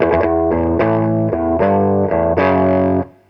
RIFF3.wav